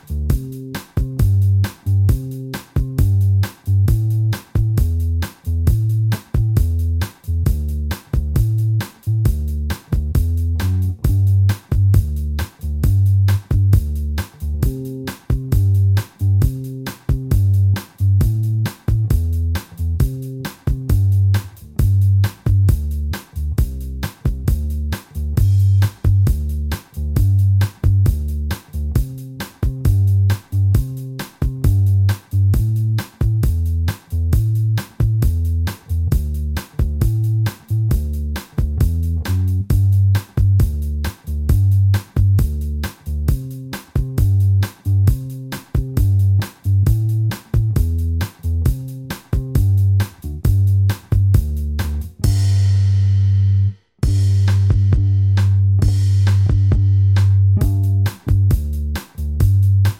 Minus Electric Guitar Soft Rock 6:16 Buy £1.50